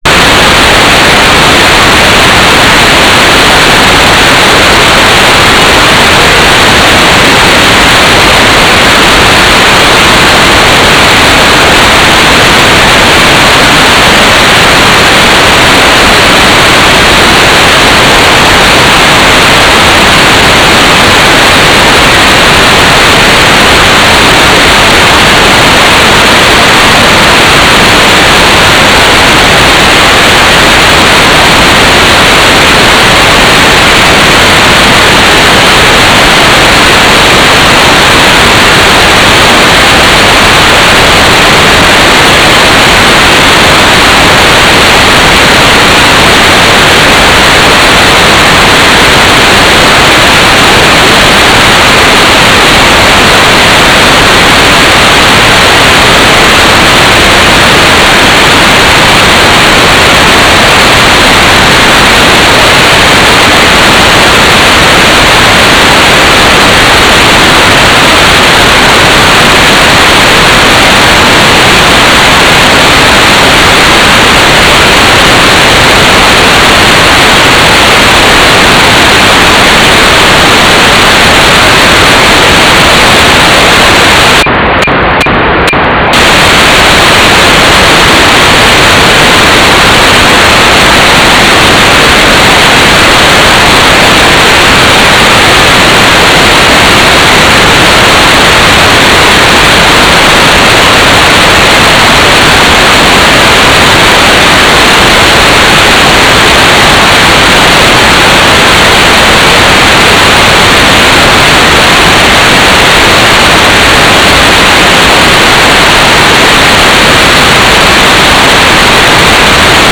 "transmitter_description": "Mode U - GMSK 4k8 AX.25 TLM",